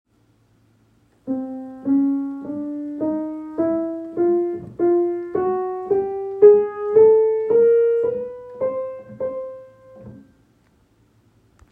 Example 2.5.2. Incorrect chromatic scale
Example of an incorrectly written chromatic scale beginning on C. The pitches are C, C-sharp, D, D-sharp, E, E-sharp, F, F-sharp, G, G-sharp, A, A-sharp, B, B-sharp, and C. The mistake is that E-sharp and F are enharmonically equivalent and B-sharp and C are enharmonically equivalent and they are both written twice.